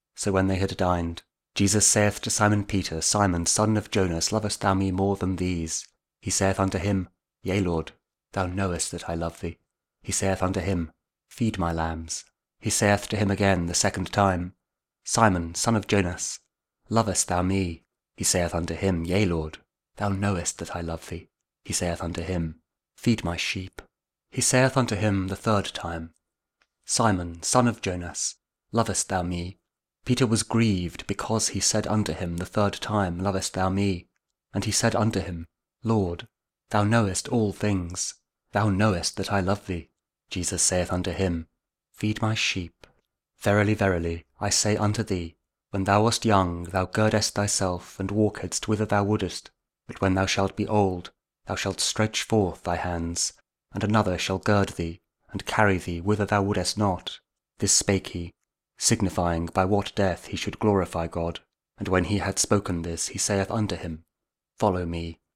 The Gospel Of Saint John 21: 15-19 | King James Audio Bible KJV | Eastertide Friday 7